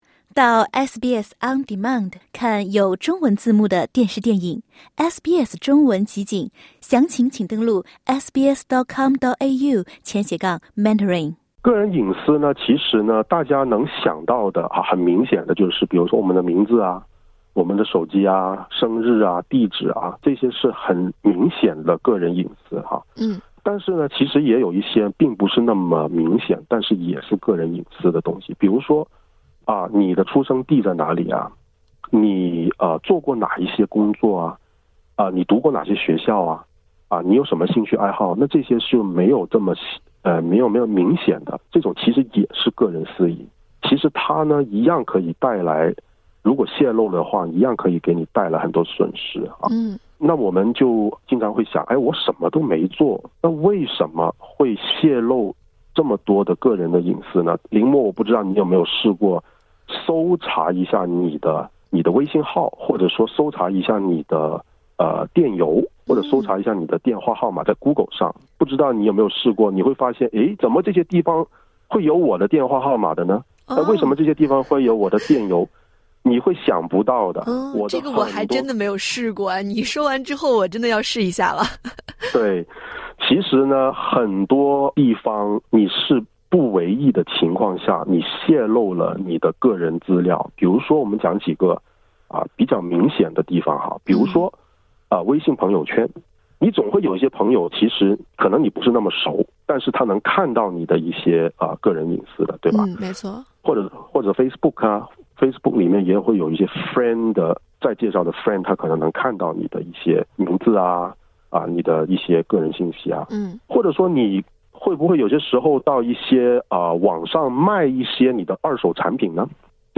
欢迎点击音频，收听完整采访。